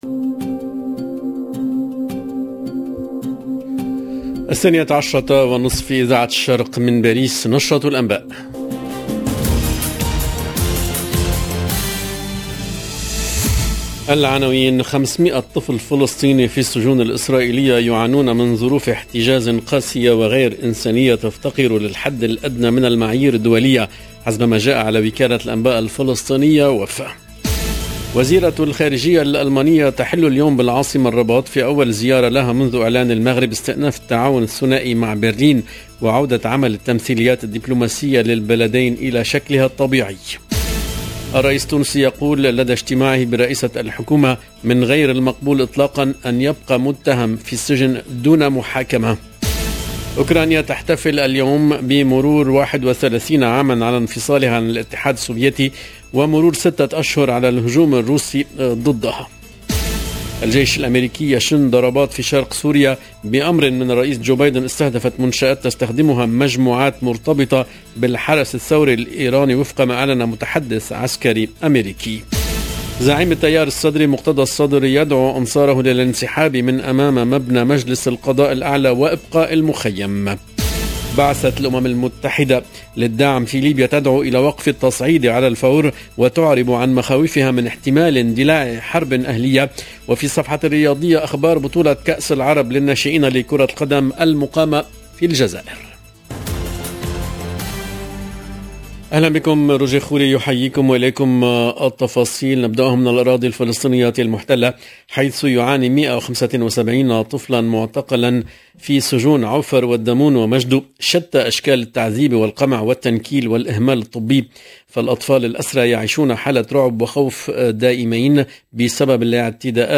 LE JOURNAL DE 12H30 EN LANGUE ARABE DU 24/8/2022